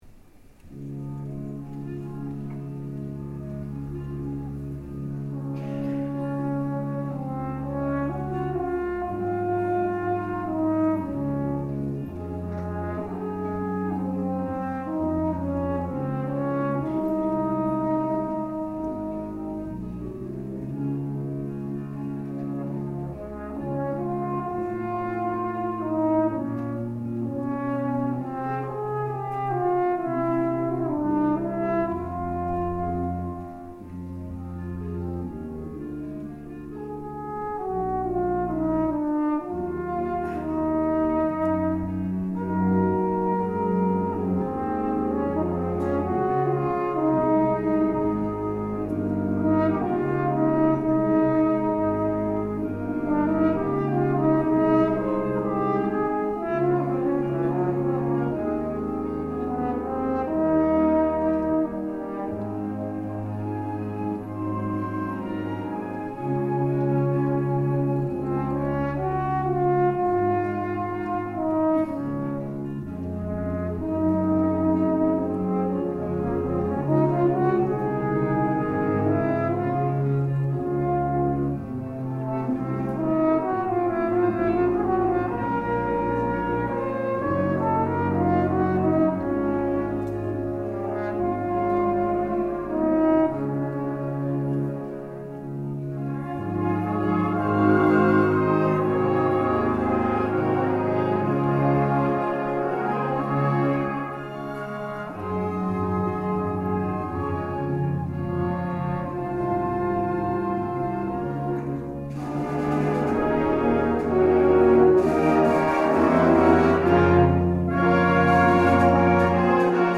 I had the opportunity to perform it several times with the University of Louisiana at Monroe’s Wind Ensemble, during a tour this past spring to Little Rock, Arkansas, and the surrounding area.
Being overshadowed by the band can be a concern for the horn soloist, but with a sensitive conductor and a responsive ensemble, most balance problems can be worked out nicely.
The recording is unedited, and I placed the Edirol in the back of the auditorium where we performed.
nocturno-with-ulm-wind-ensemble.mp3